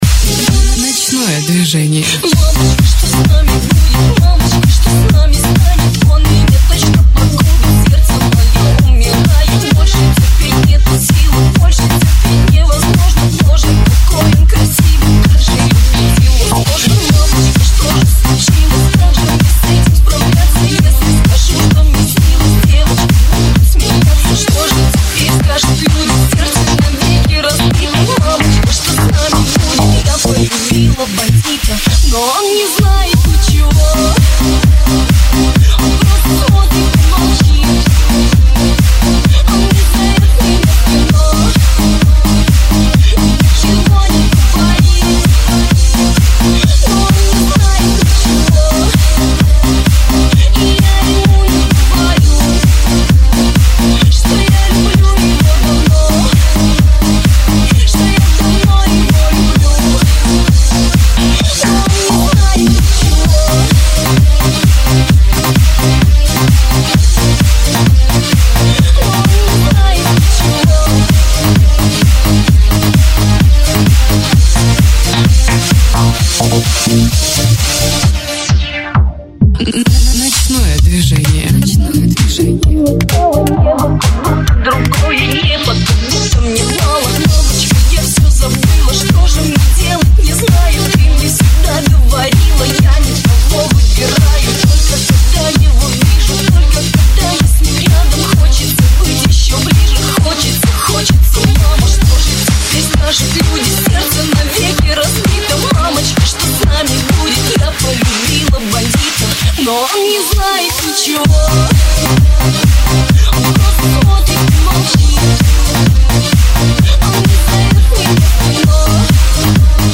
Категория: Русские